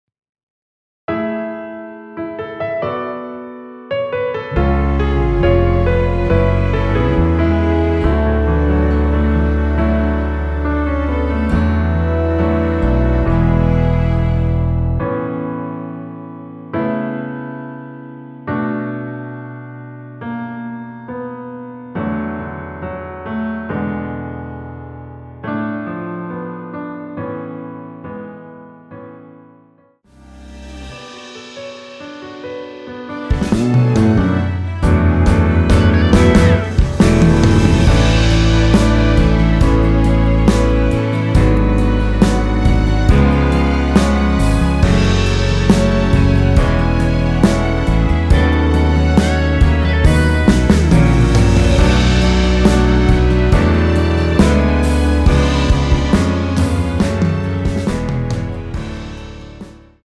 원키에서(-3)내린 MR입니다.
◈ 곡명 옆 (-1)은 반음 내림, (+1)은 반음 올림 입니다.
앞부분30초, 뒷부분30초씩 편집해서 올려 드리고 있습니다.
중간에 음이 끈어지고 다시 나오는 이유는